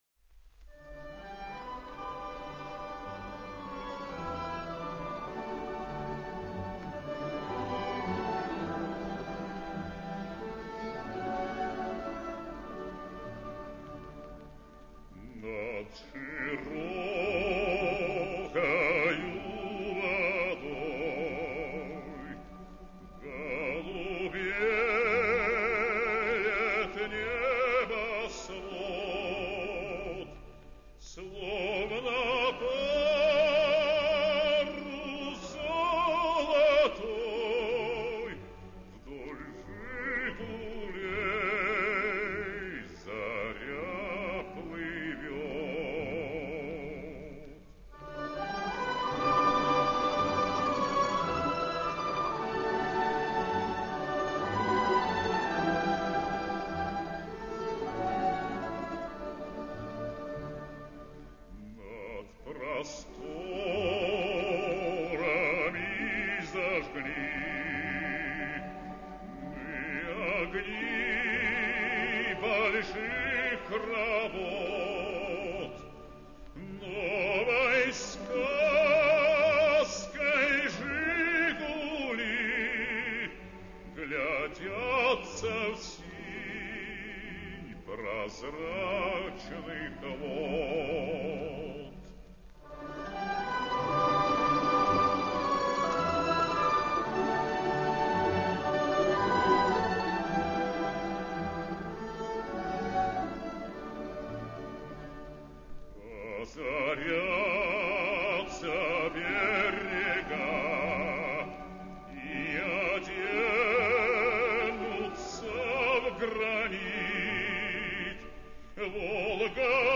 оркестр народных